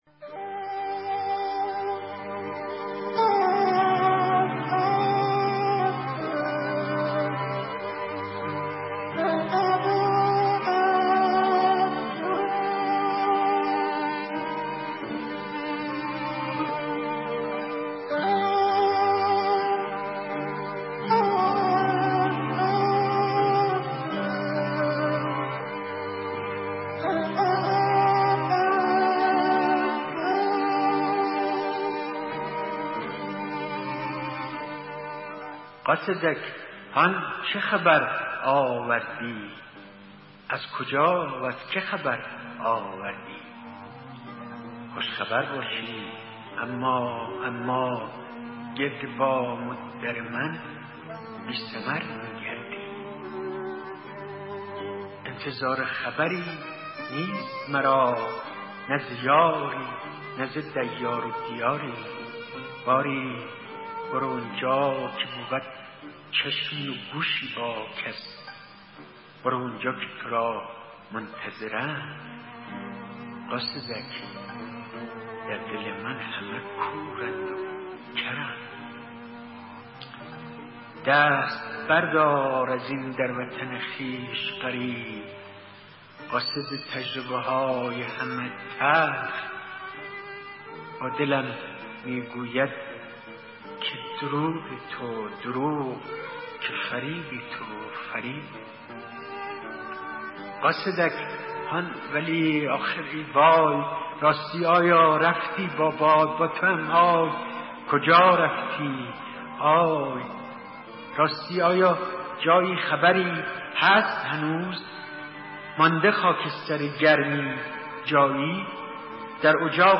فایل صوتی دکلمه شعر قاصدک با صدای مهدی اخوان ثالث